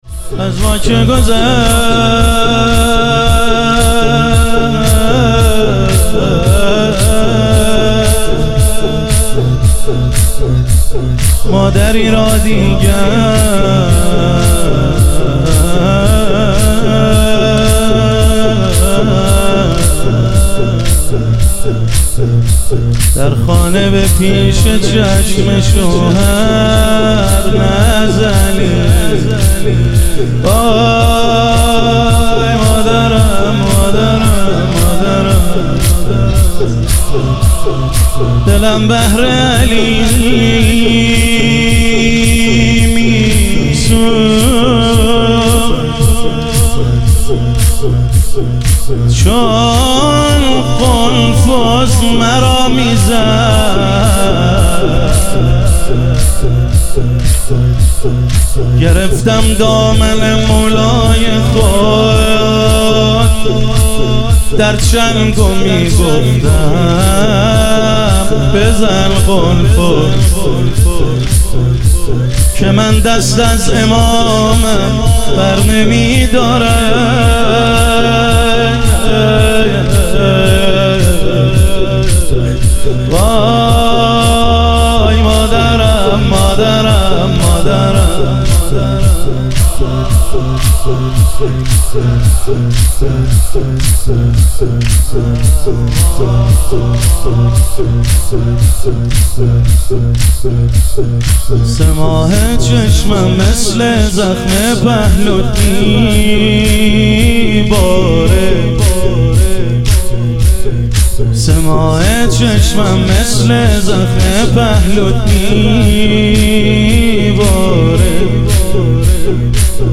ایام فاطمیه اول - تک